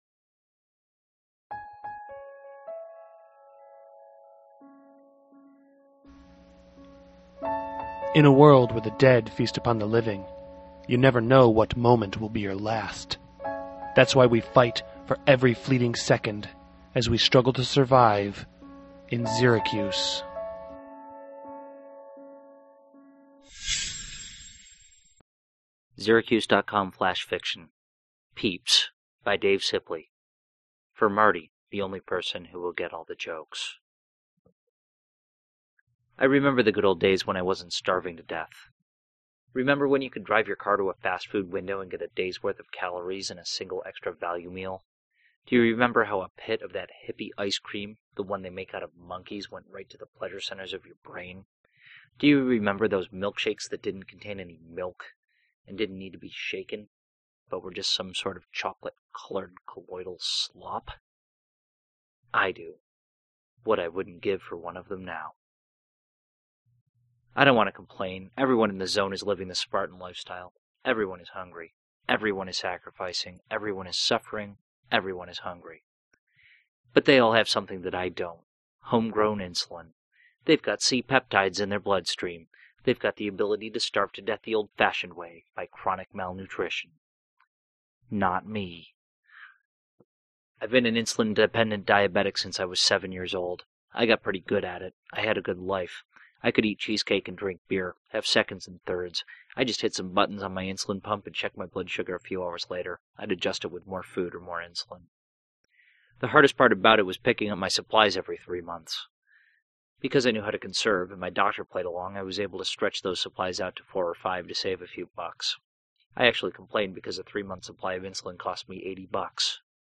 I actually tried to fix the low recording levels that were showing until I listened to what happened when I got up close to the mike.